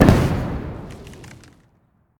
poison-capsule-explosion-5.ogg